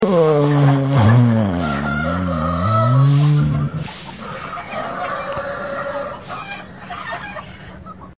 Pig resting sound effects free download